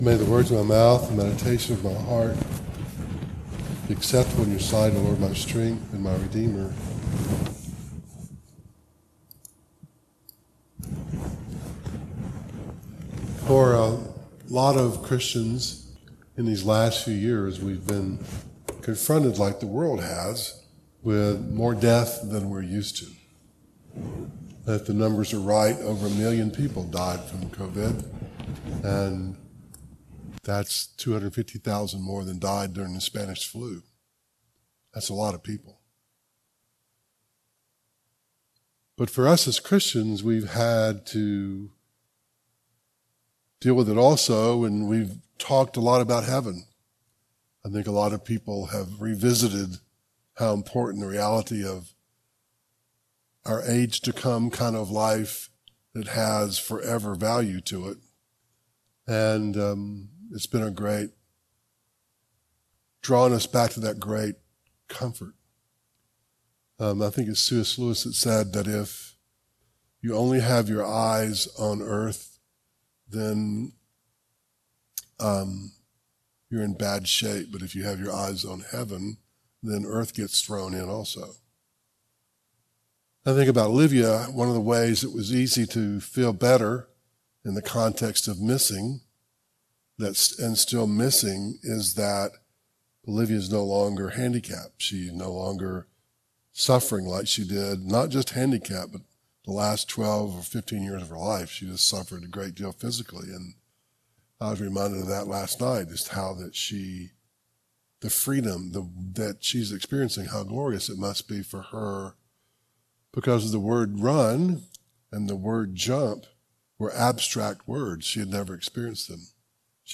Genesis 32:24-31 Service Type: Devotional